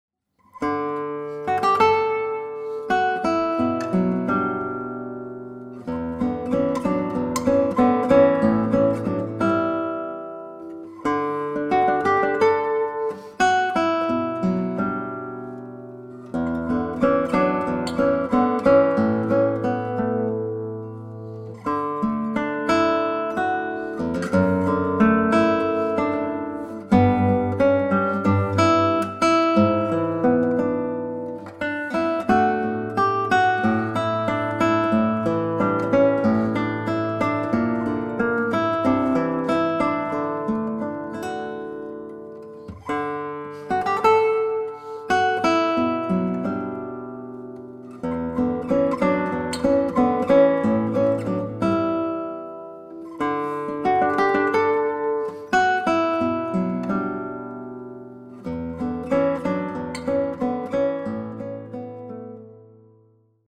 Für Gitarre
Neue Musik
Gitarre (1)